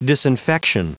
Prononciation du mot disinfection en anglais (fichier audio)
Prononciation du mot : disinfection